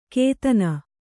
♪ kētana